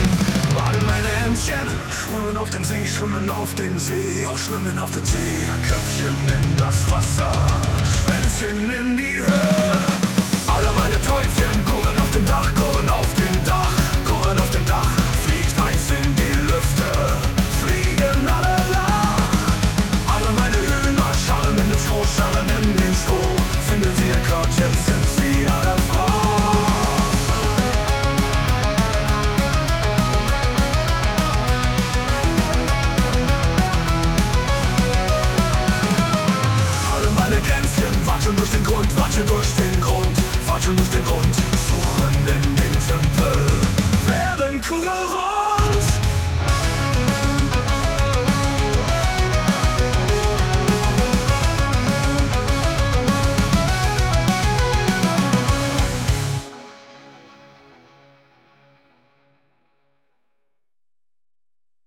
Alle meine Entchen – Metall [Heavy Metal, aggressive electric guitars, double bass drums, powerful male vocals, epic, intense]